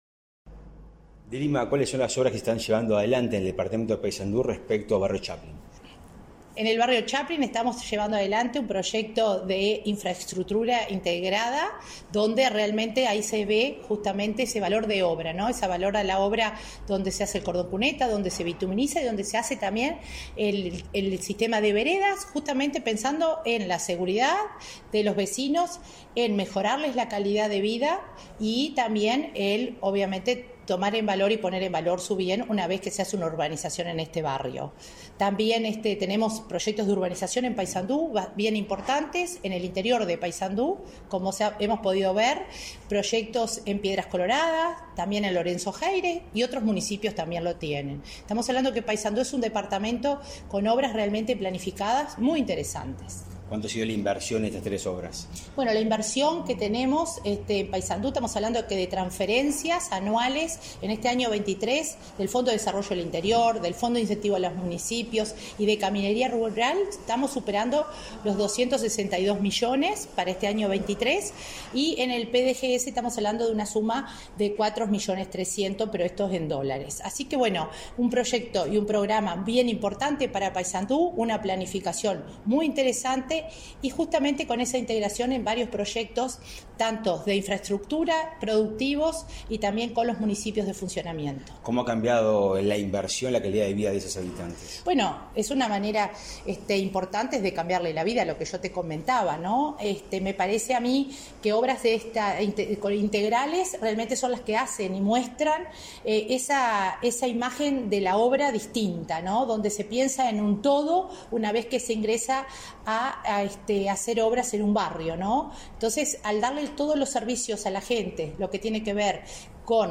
Entrevista a la coordinadora de Descentralización y Cohesión de la OPP, María de Lima